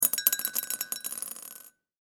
サイコロ・ダイス | 無料 BGM・効果音のフリー音源素材 | Springin’ Sound Stock
陶器でチンチロリン2.mp3